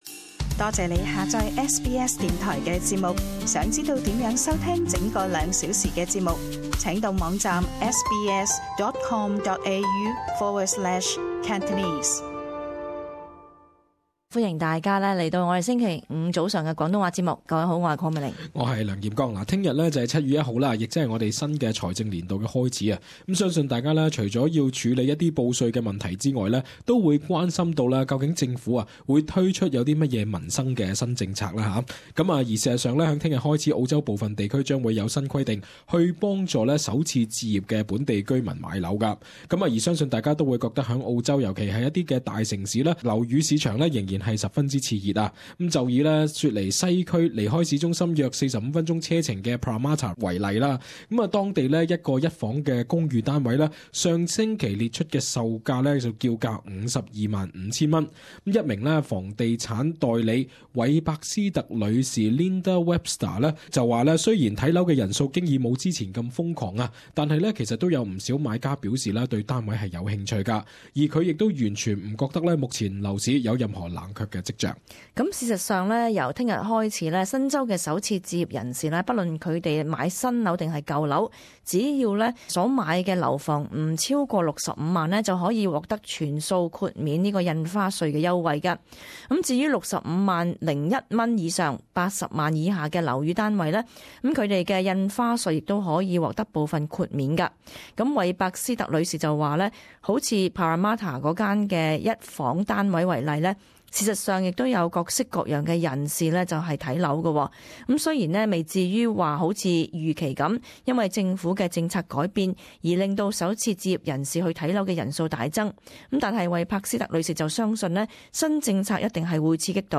【時事報導】新財政年度政府推新策助首置人士上車